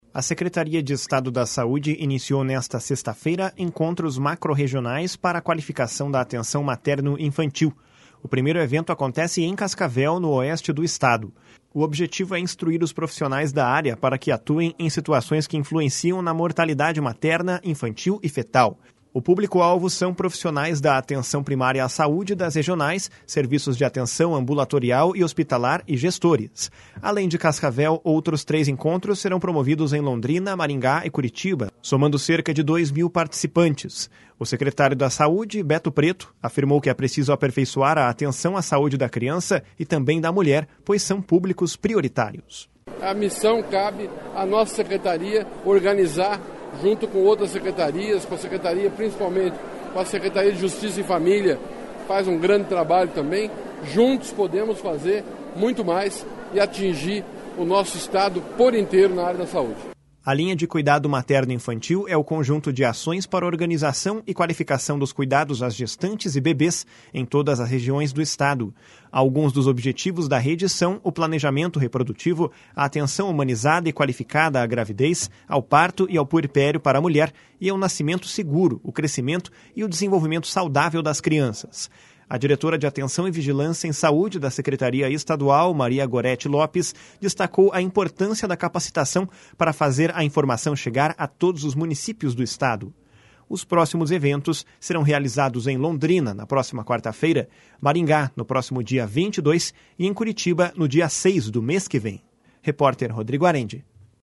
O secretário da Saúde, Beto Preto, afirmou que é preciso aperfeiçoar a atenção à saúde da criança e também da mulher, pois são públicos prioritários. // SONORA BETO PRETO //